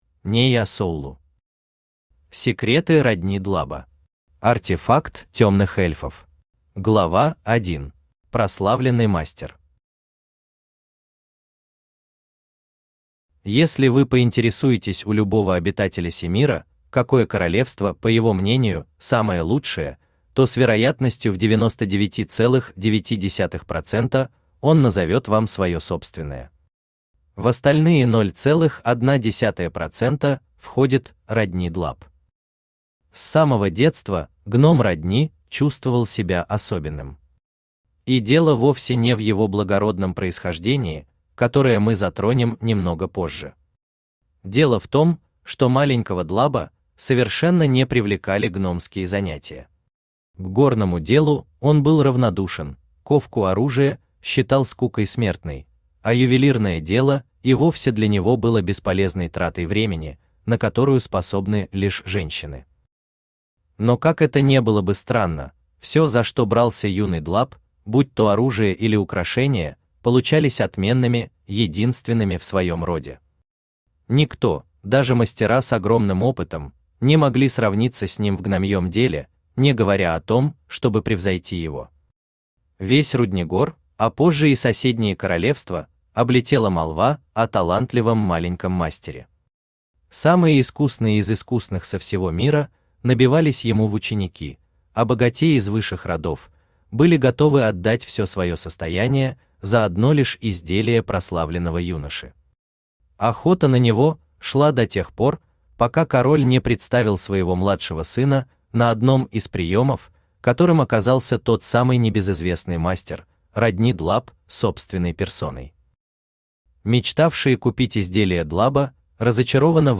Аудиокнига Секреты Родни Длаба | Библиотека аудиокниг
Aудиокнига Секреты Родни Длаба Автор Нея Соулу Читает аудиокнигу Авточтец ЛитРес.